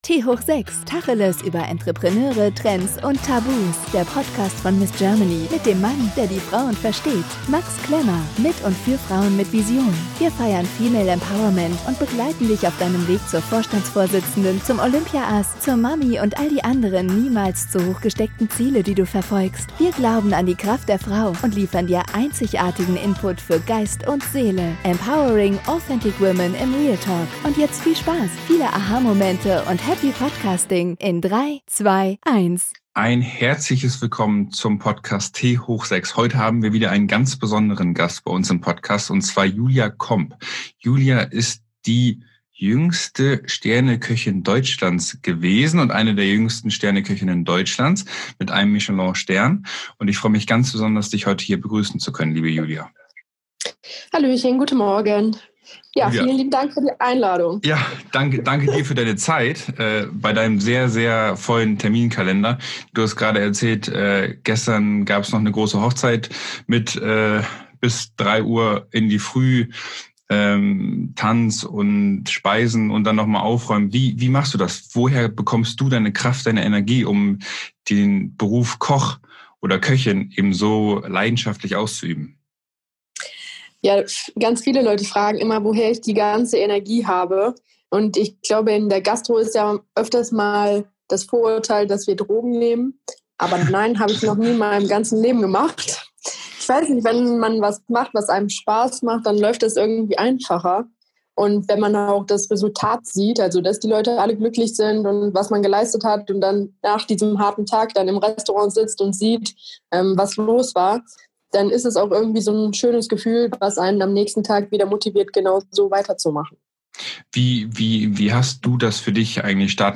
Wir sprechen im Tanga-Talk mit interessanten Frauen über Trends, aber genauso auch über Tabus.